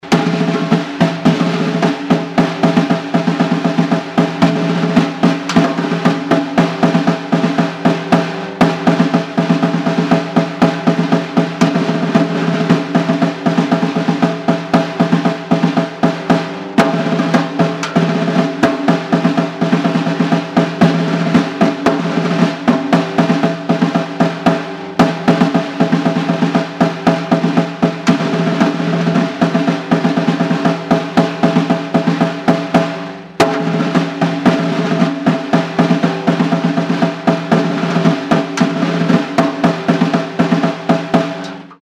На этой странице собраны разнообразные звуки малого барабана: от четких ударов до сложных ритмических рисунков.
Звучание барабанов на параде